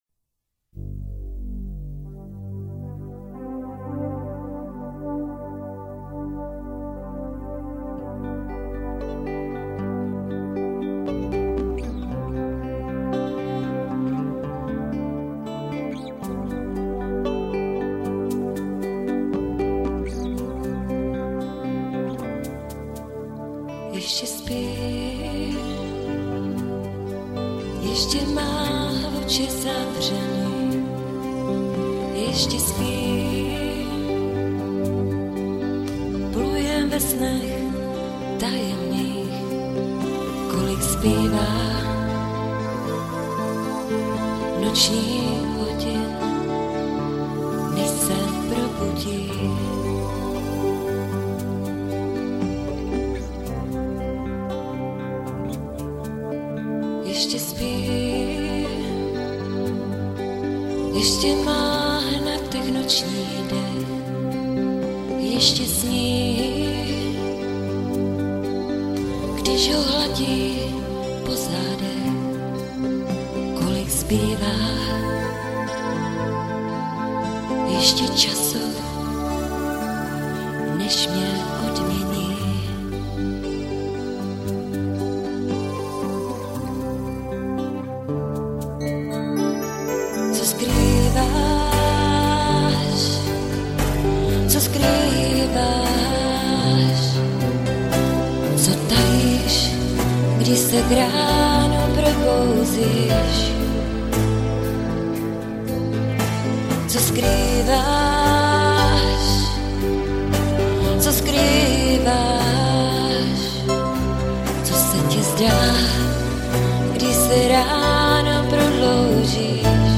Žánr: Rock